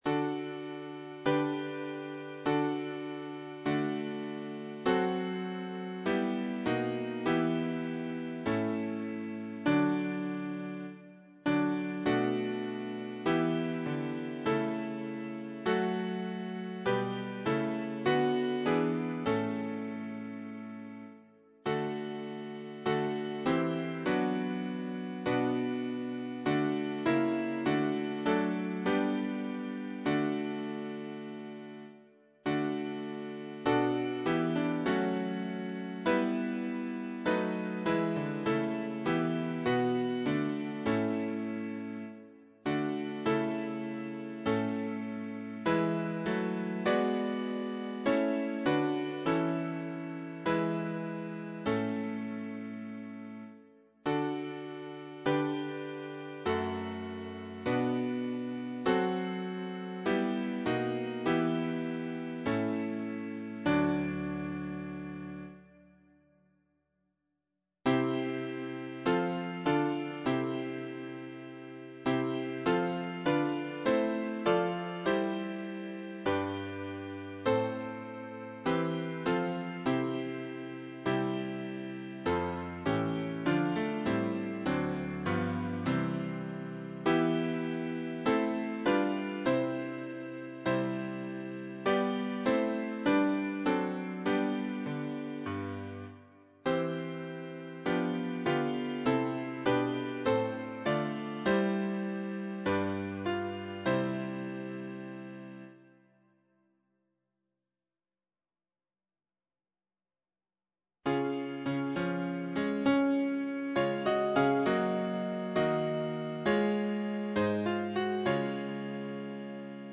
choir SATB
Hymns, psalms and prayers